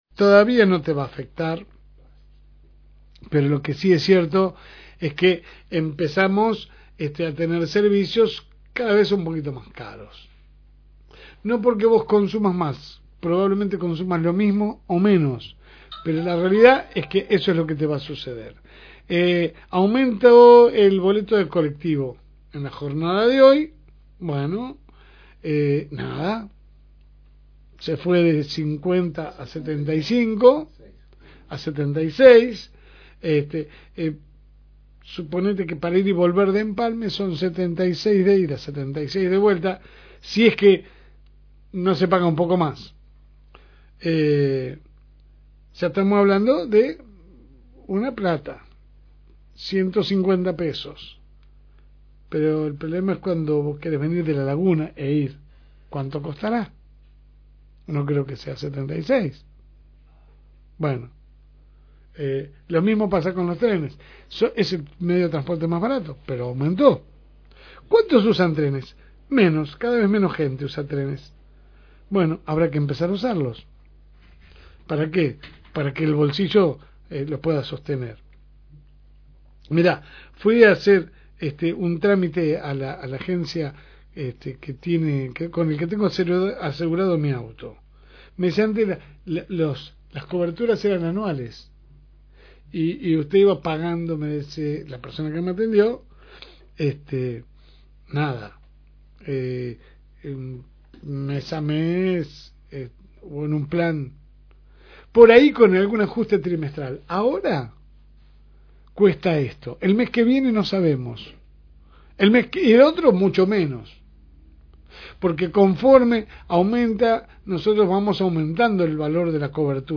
editorial (2)